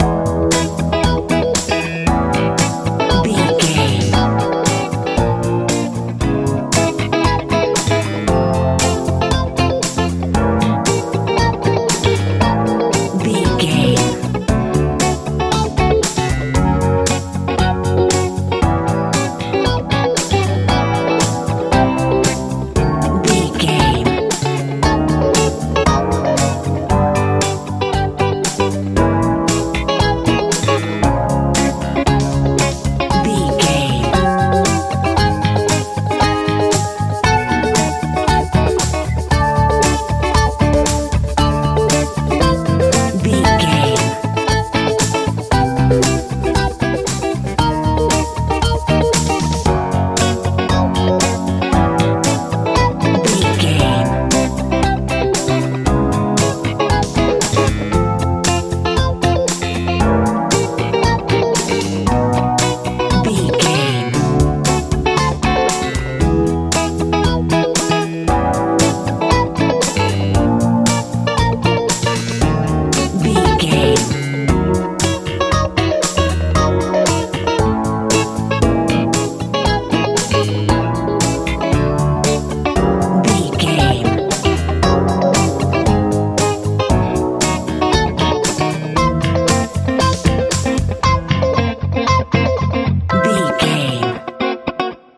disco feel
Ionian/Major
groovy
funky
electric guitar
synthesiser
bass guitar
drums
70s
80s